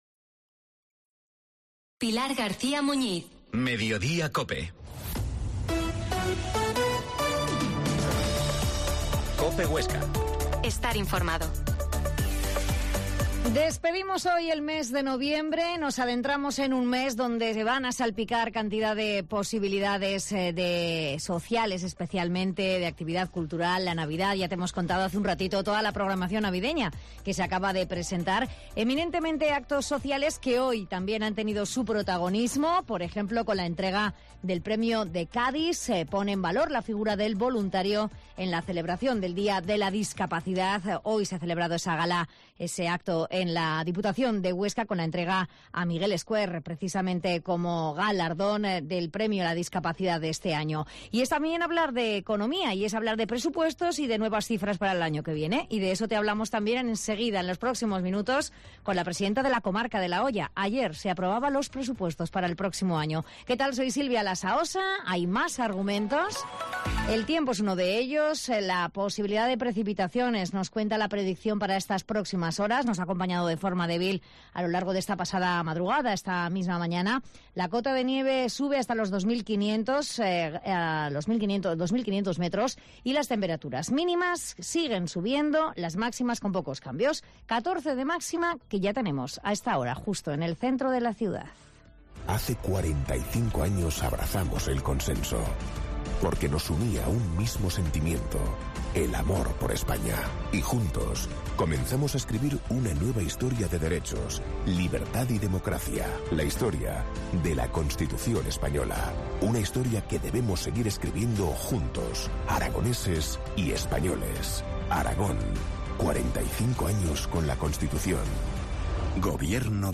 Mediodia en COPE Huesca 13.50 Entrevista a la Presidenta de la comarca de la Hoya, Mónica Soler